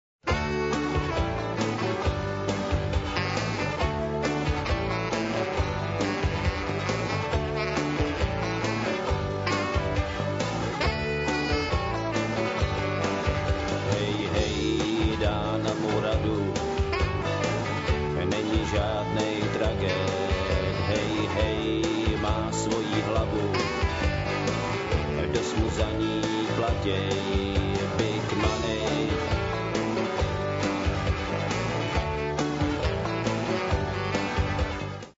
nejlepší česká undergroundová skupina 80.let